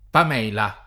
pam$la; ingl. ^milë] pers. f. — ted. Pamela [pam%ela o pam$la] o Pamele [pam%elë o pam$] — figura femminile proposta da un romanzo di S. Richardson («Pamela or virtue rewarded», 1740) con un nome tolto a un personaggio di un romanzo pastorale di Ph. Sidney («Arcadia», 1590), dove però aveva pn. piana [ingl. päm&ilë] — dal successo del Richardson la fortuna della pn. sdrucciola da lui scelta nella lingua ingl., e del suo tipo di personaggio femminile nella letteratura d’altre nazioni, ma qui con l’originaria pn. piana: così in partic. in Italia, grazie alle commedie di C. Goldoni («Pamela nubile», 1750; «Pamela maritata», 1760) e d’altri autori — dal nome di persona il s. f. pamela [pam$la], nome dato a un cappello femminile di paglia, diffuso nella moda giovanile del ’700 e ’800